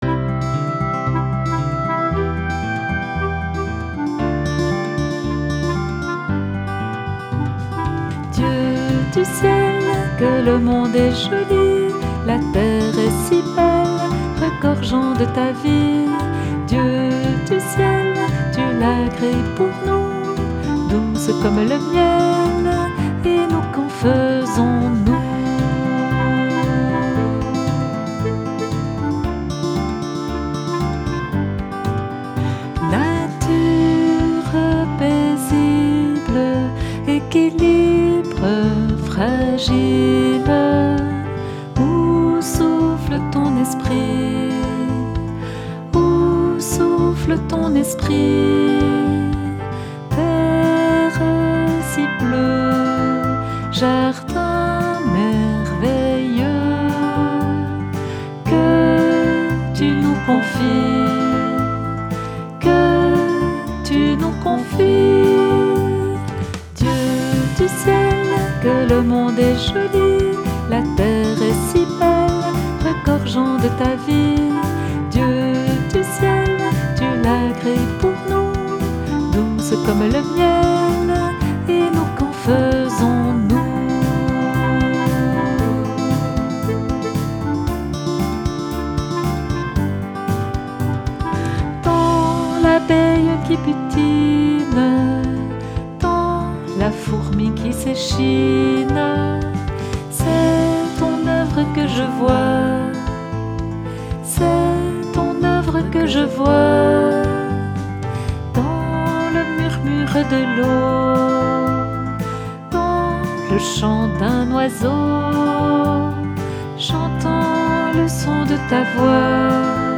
Chant « Création »